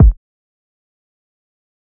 Kick_Rackz.wav